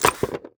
UI_Putdown_BoneTablet.ogg